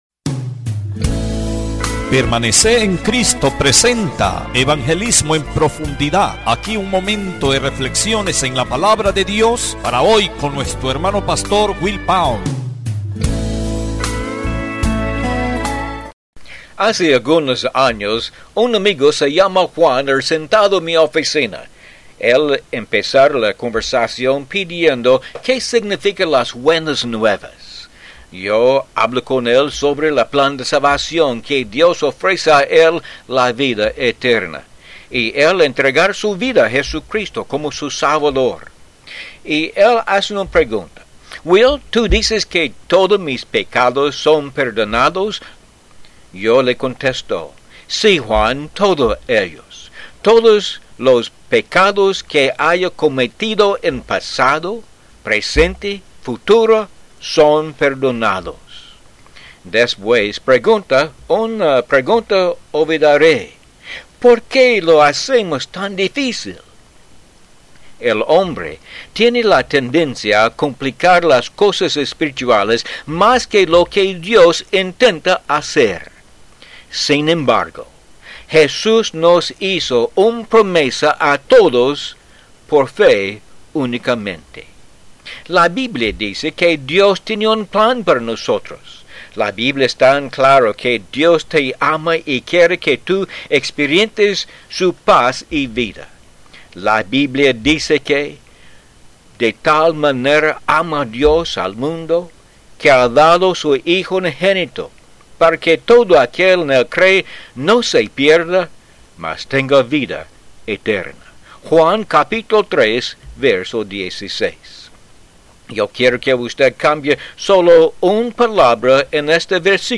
Disfrute estos programas de cinco minutos Cristianos de radio.